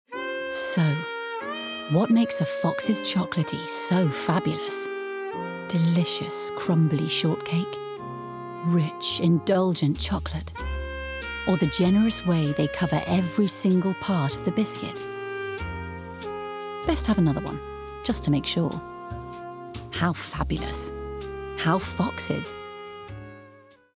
30/40's Neutral/RP,
Calm/Reassuring/Intelligent
Commercial Showreel CoOpBank Oliver’s Travels Sainsbury’s VW Nurofen